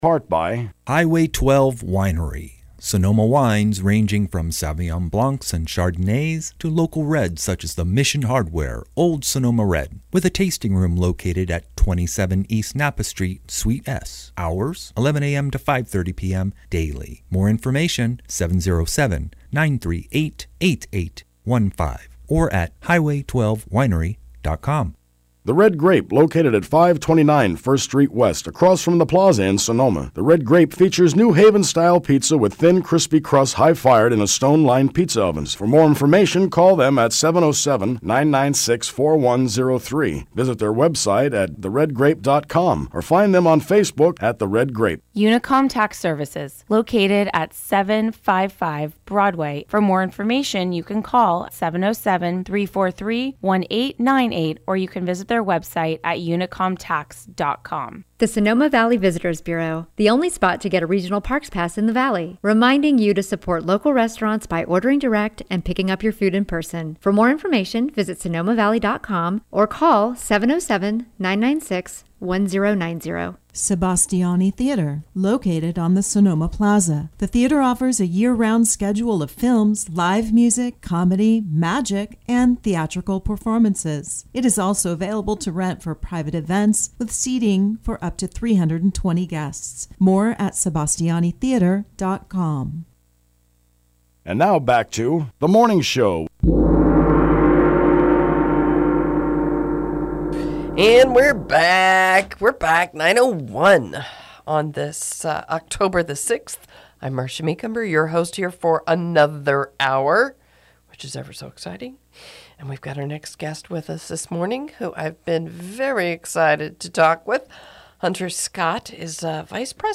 Radio Interview